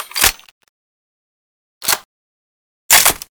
ump45_reload.wav